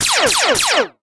enemylasers1.ogg